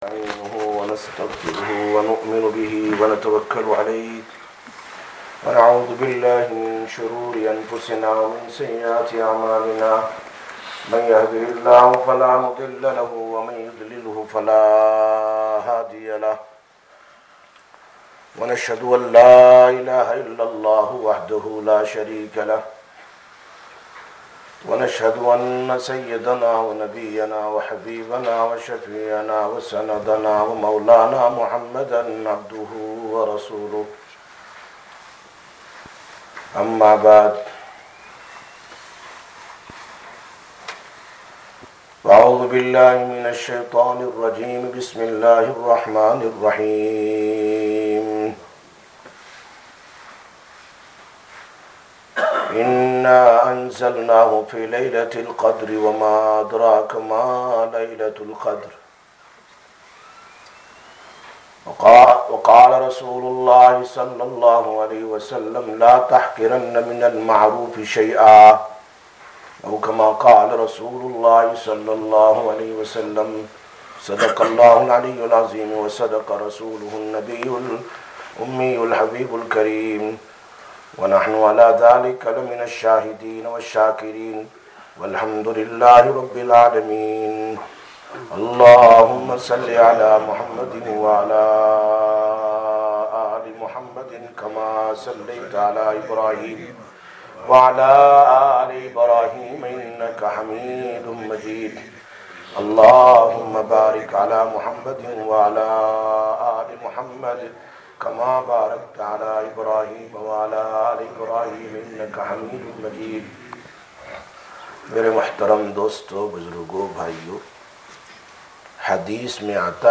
22/04/2022 Jumma Bayan, Masjid Quba